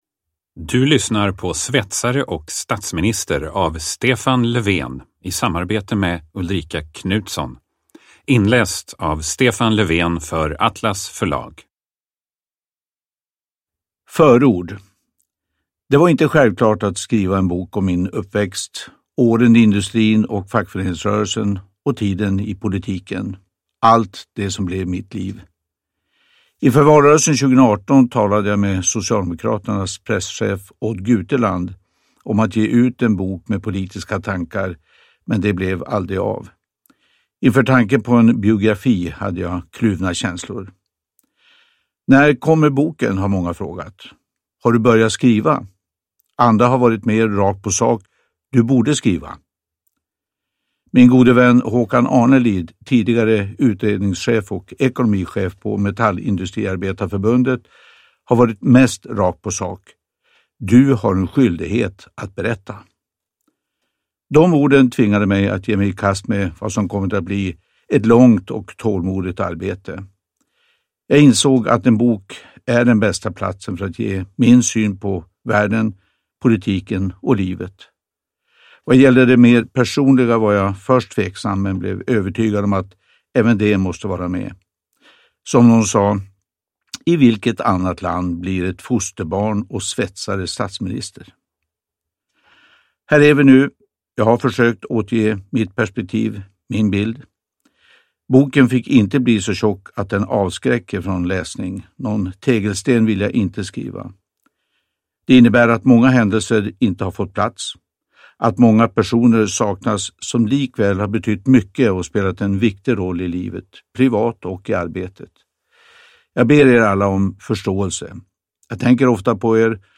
Uppläsare: Stefan Löfven
Ljudbok